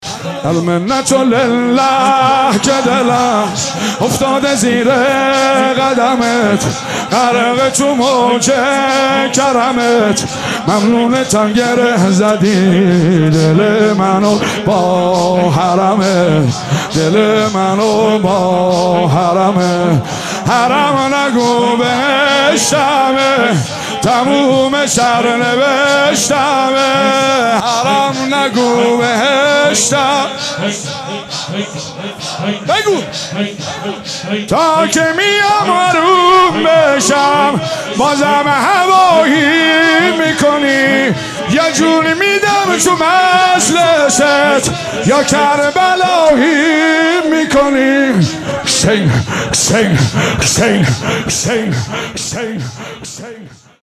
مناسبت : شب پنجم محرم
قالب : شور